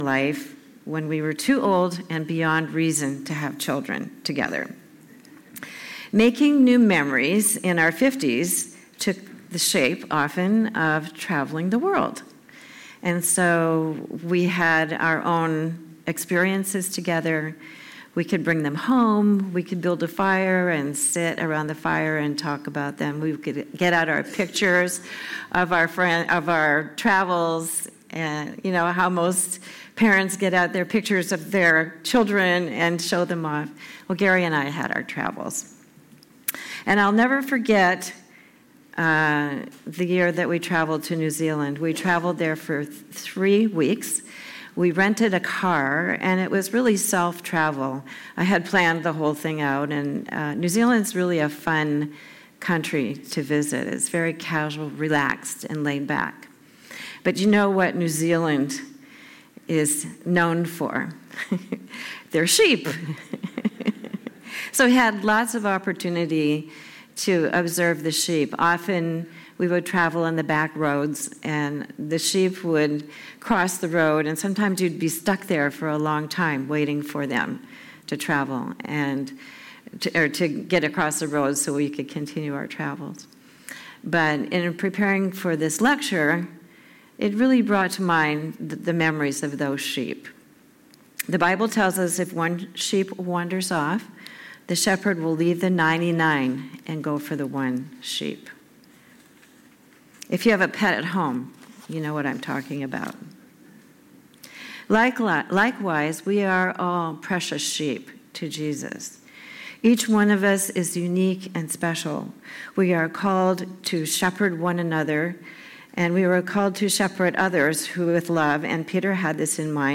Download PDF Print Week 9 Message March 23, 2023 Week Nine “Being The Church” 1 Peter 5:1-7 Vs. 1-4 Shepherding the flock of God Vs. 5-6 Submission and humility Vs. 7 Trusting in His care Discussion Questions How does spiritual leadership compare to shepherding sheep?